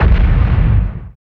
50 XPL KIK-L.wav